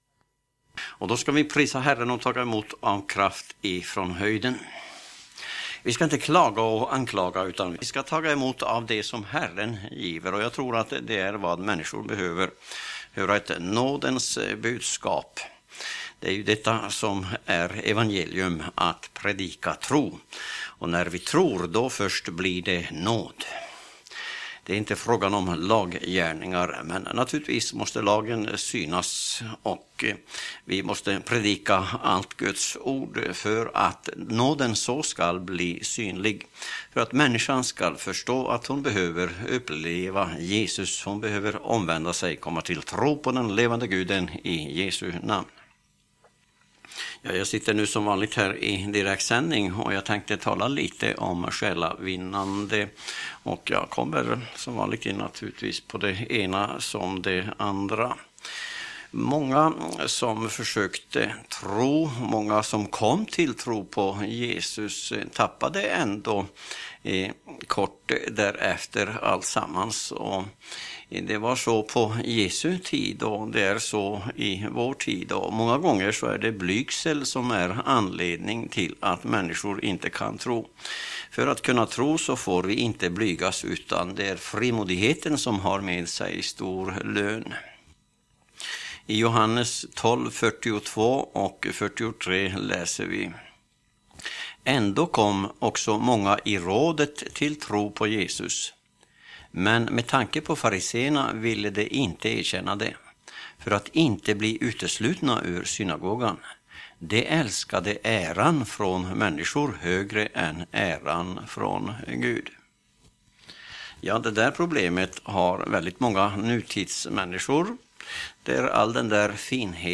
predikan_frimodighet.mp3